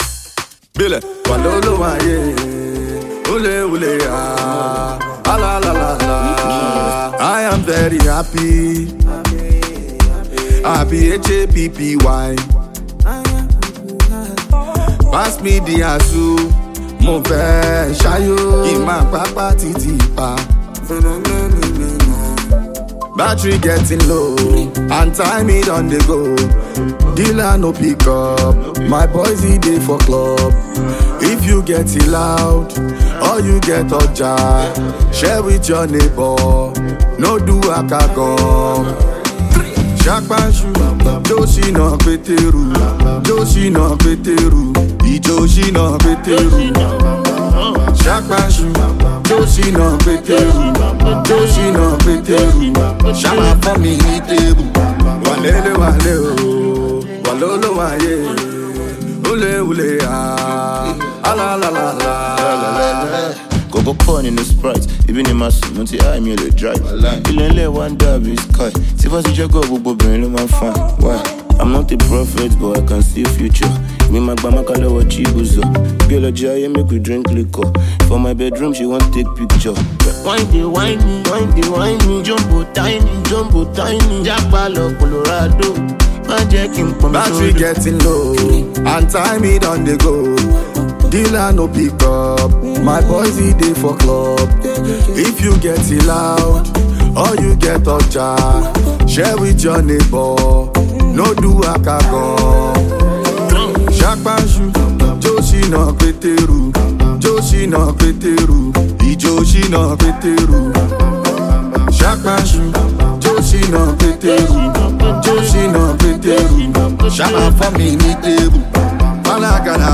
With its catchy beat, infectious melody, and powerful lyrics